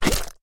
sounds / mob / slime / attack1.mp3